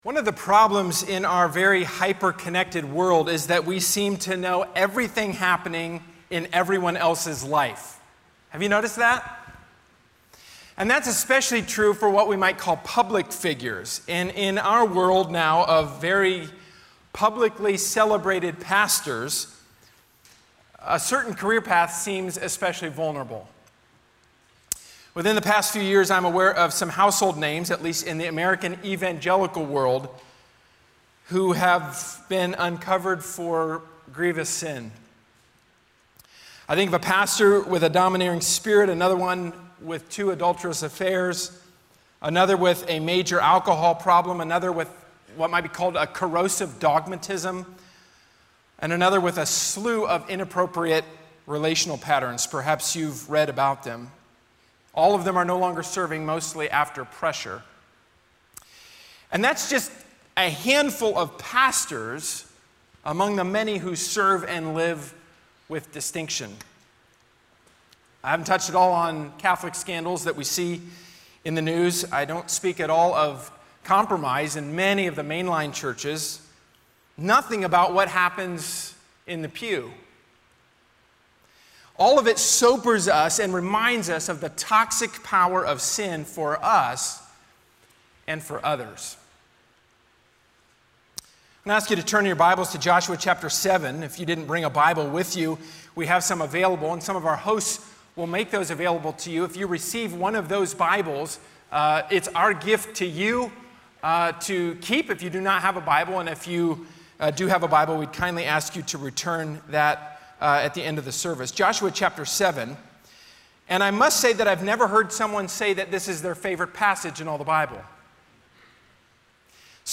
A sermon from the series "Again & Again."